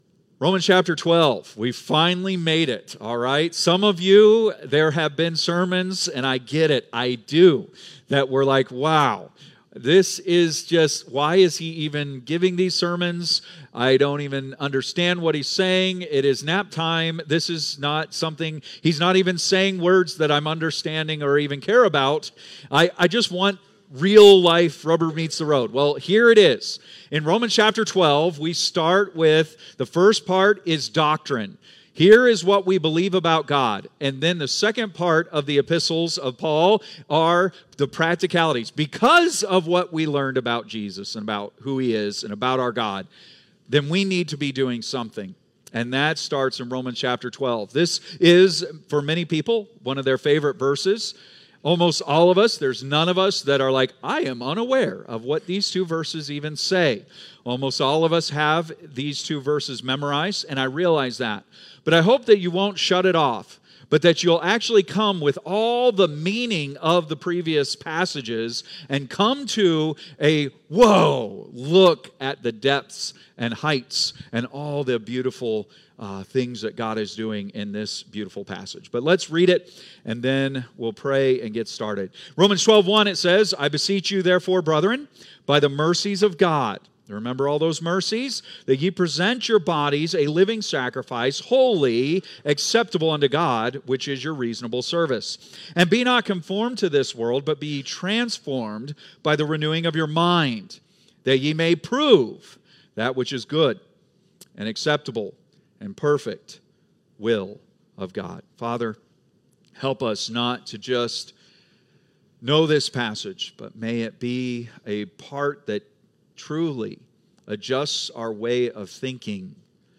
Great sermon!
Date: May 11, 2025 (Sunday Morning)